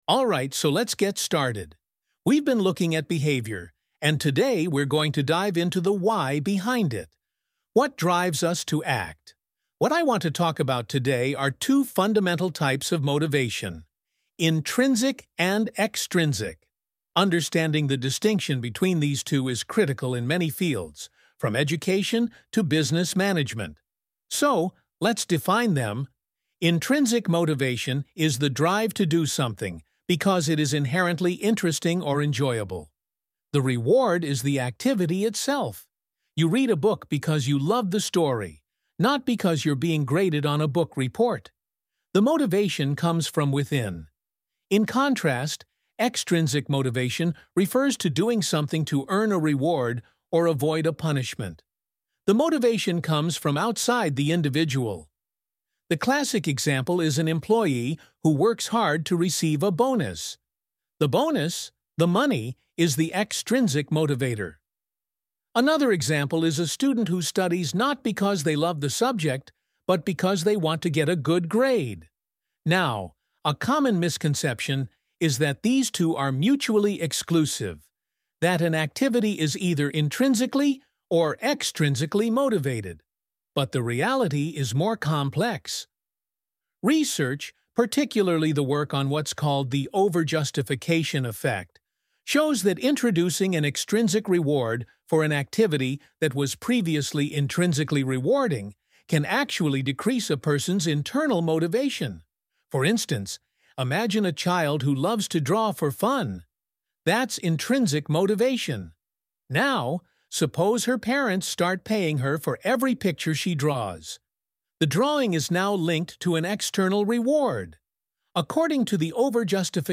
1. What is the main purpose of the lecture?